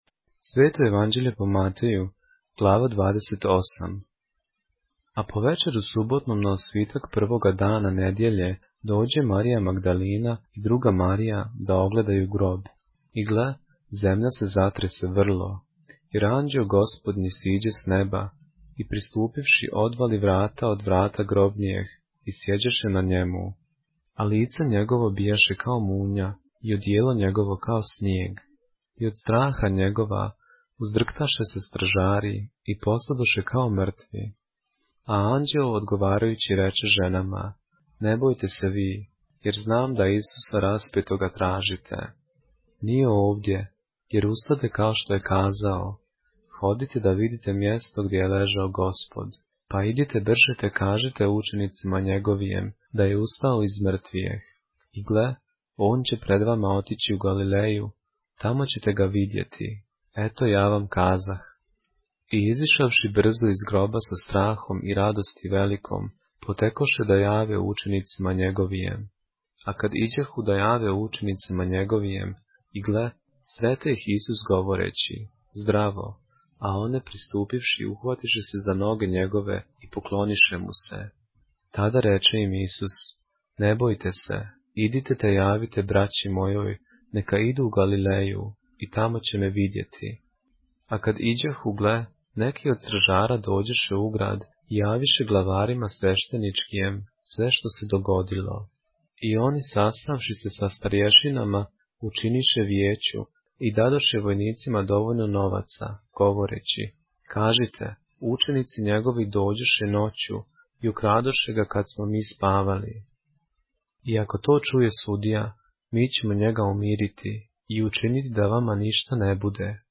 поглавље српске Библије - са аудио нарације - Matthew, chapter 28 of the Holy Bible in the Serbian language